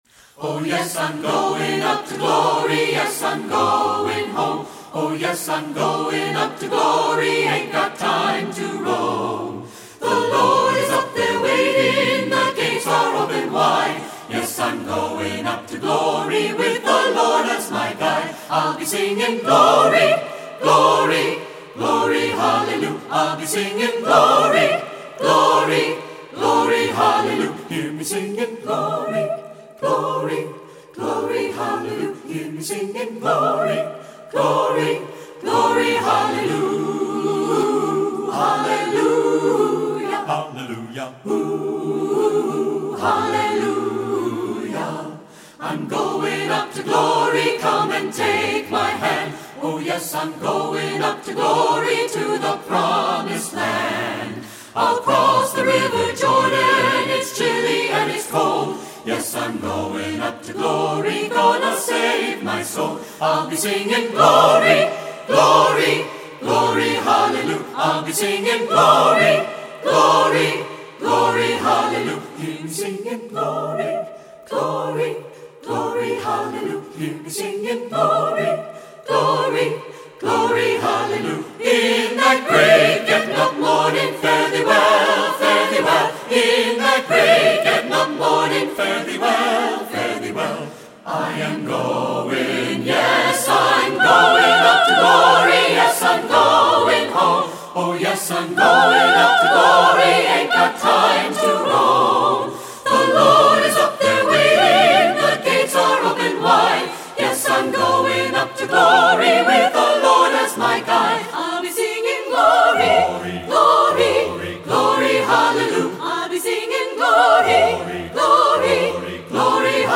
Voicing: SATB a cappella